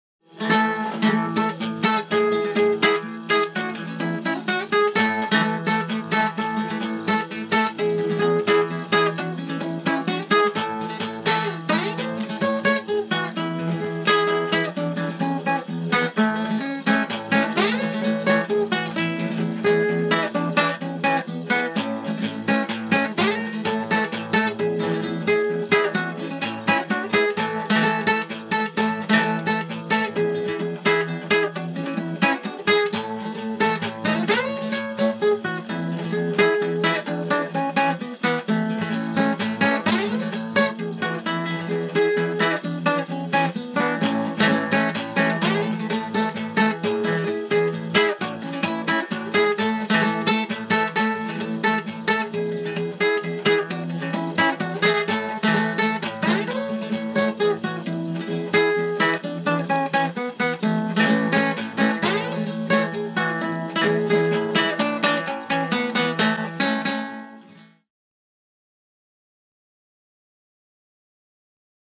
Cueca instrumental interpretada en guitarra
Música tradicional
Folklore
Cueca